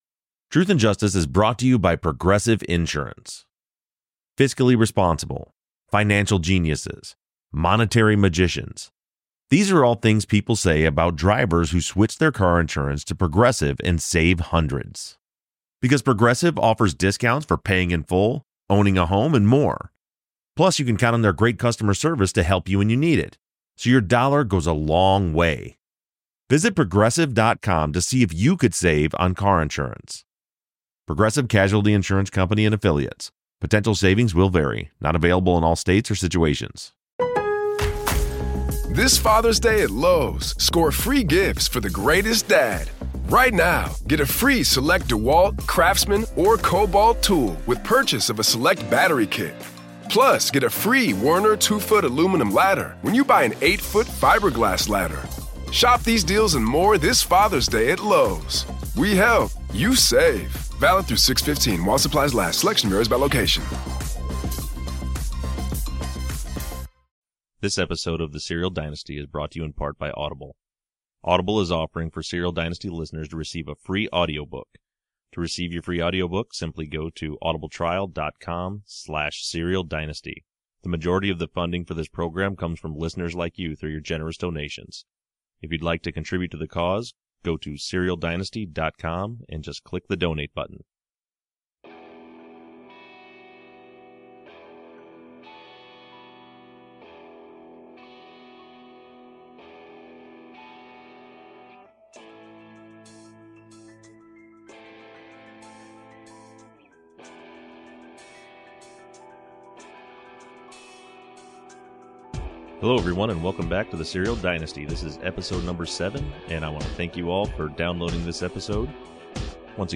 Listeners Call Into the Show